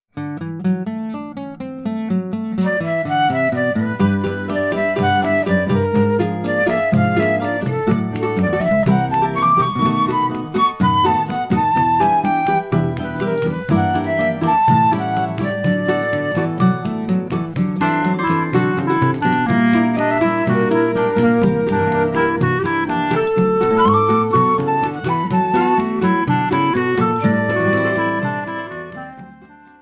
clarinet
Choro ensemble in the other songs